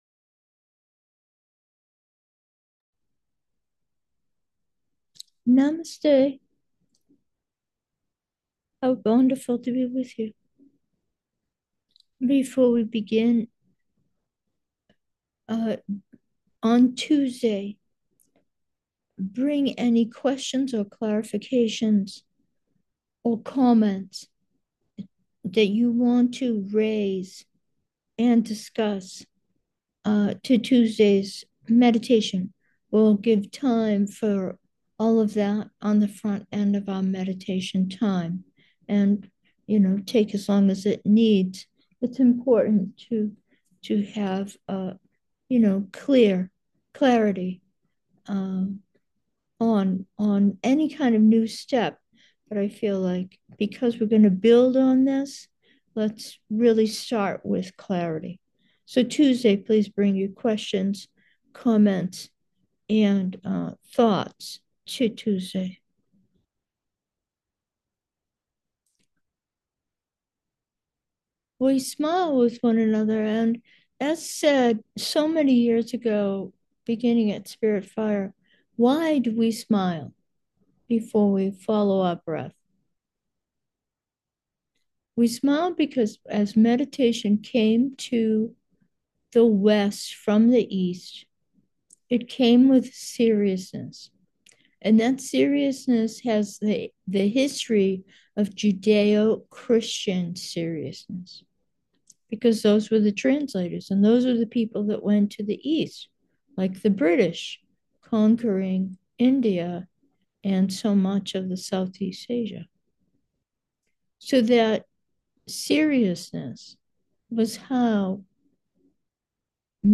Meditation: outbreath 3